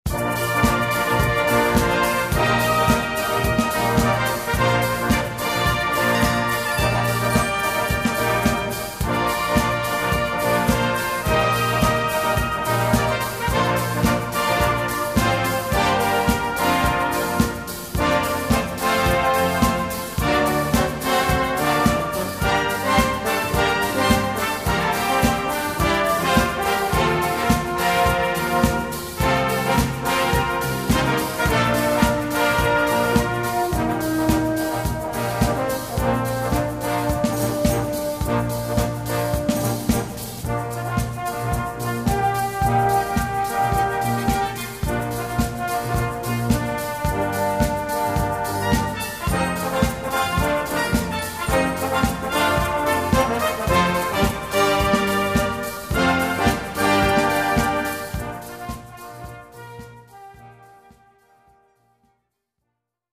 Recueil pour Brass band